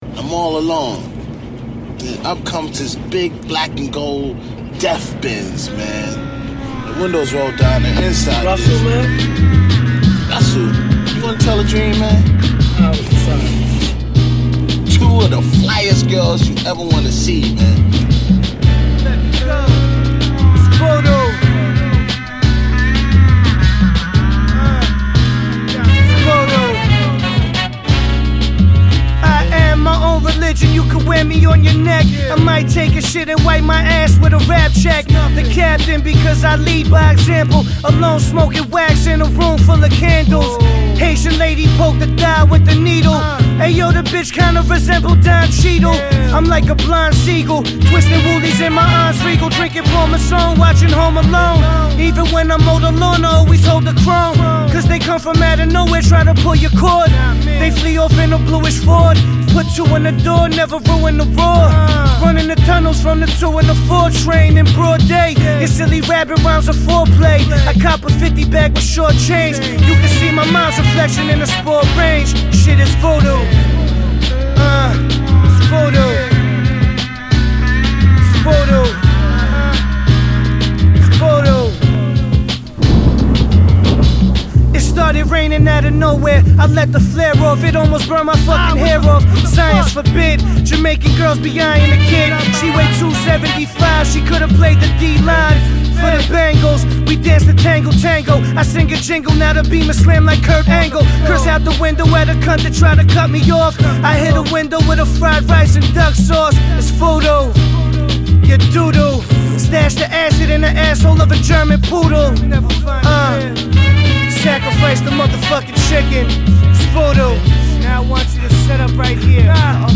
witty word play